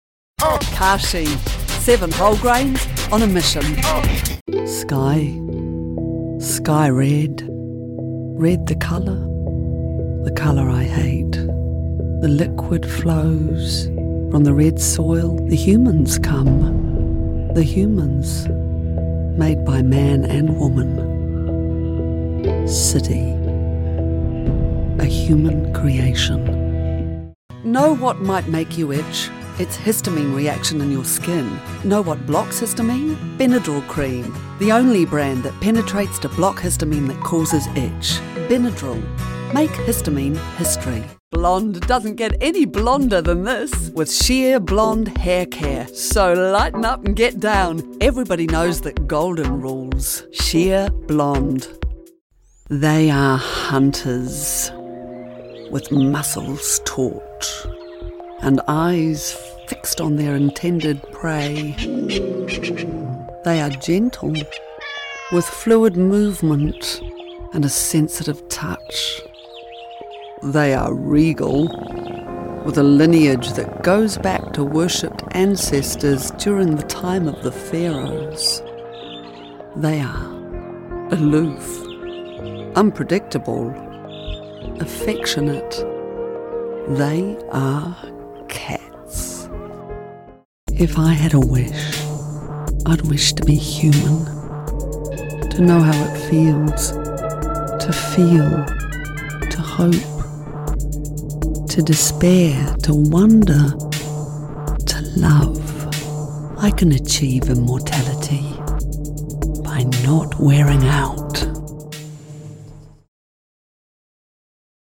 Demo
Adult, Mature Adult
new zealand | natural
COMMERCIAL 💸
comedy
explainer video